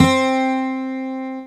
1 channel
12GUIT3.mp3